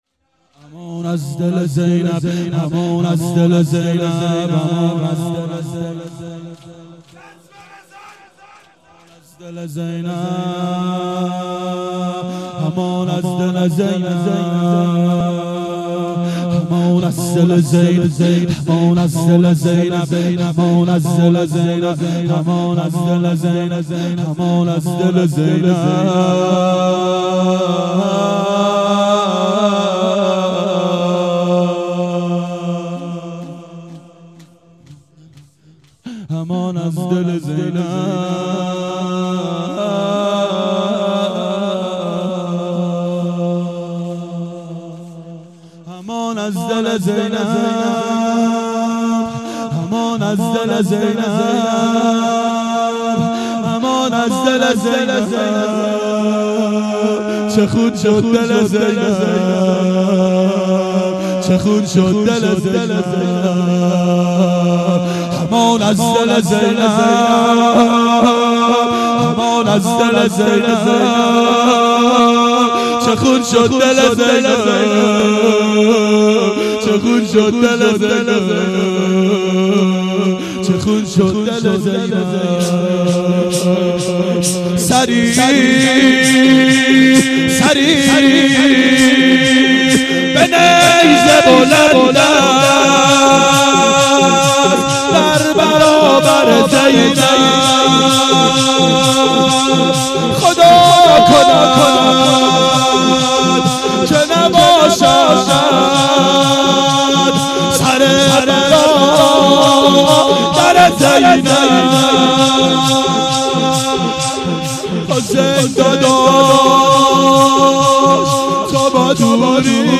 دهه اول صفر سال 1391 هیئت شیفتگان حضرت رقیه سلام الله علیها (شب اشهادت)